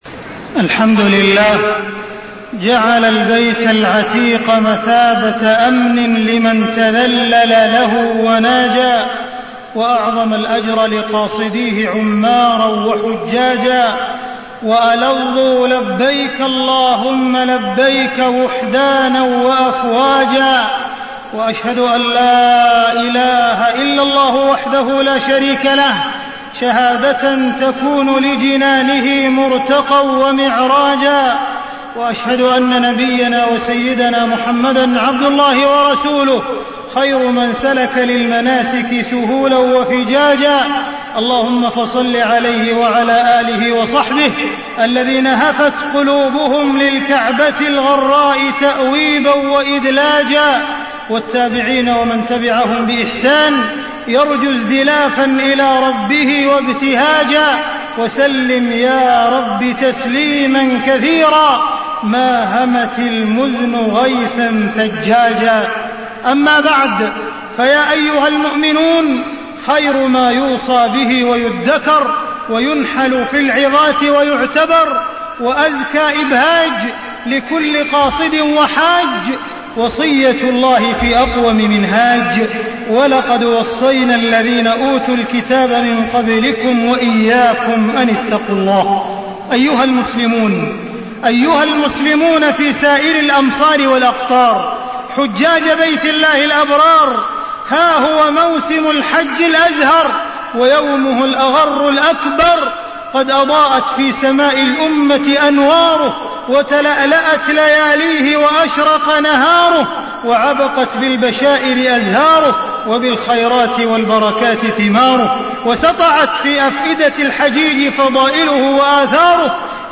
تاريخ النشر ٣٠ ذو القعدة ١٤٢٩ هـ المكان: المسجد الحرام الشيخ: معالي الشيخ أ.د. عبدالرحمن بن عبدالعزيز السديس معالي الشيخ أ.د. عبدالرحمن بن عبدالعزيز السديس الحج وأهميته